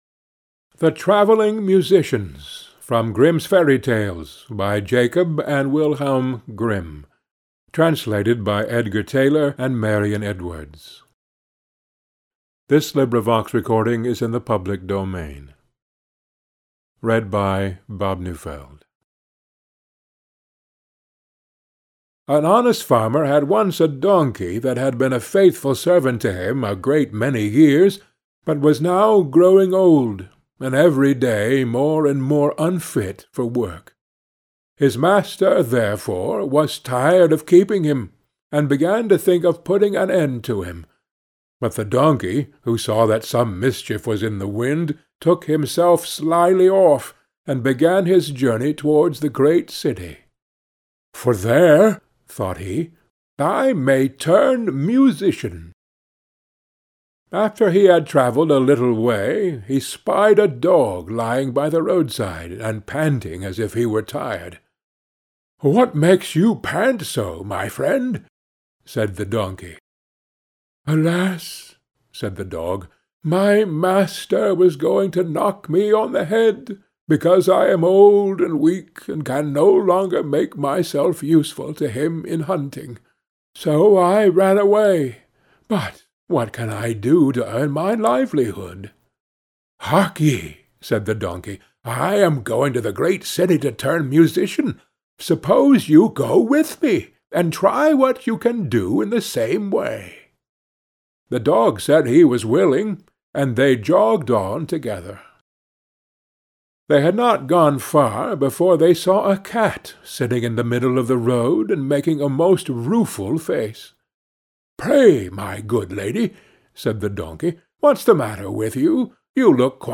Reading time 11 min ✓ All Grimm fairy tales in original version ✓ Online fairy tale book with illustrations ✓ Sorted by reading time ✓ Mp3-Audiobooks ✓ Without advertising